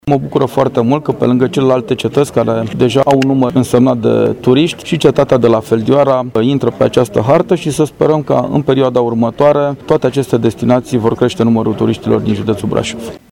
Investiția are o valoare de peste 12 milioane de lei și va aduce județului Brașov, încă un obiectiv turistic important, așa cum este de părere președintele CJ Brașov, Adrian Veștea: